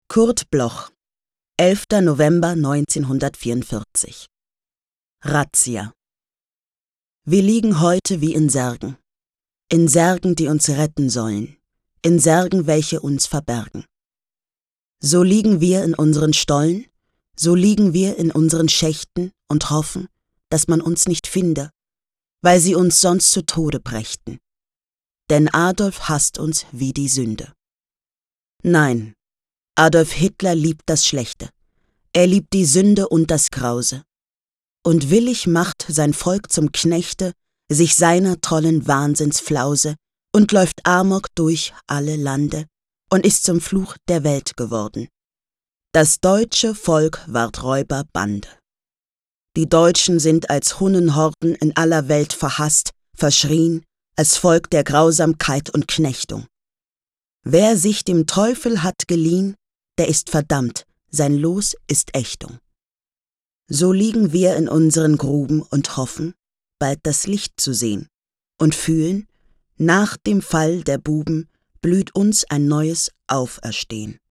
Aufnahme: der apparat, Berlin · Bearbeitung: Kristen & Schmidt, Wiesbaden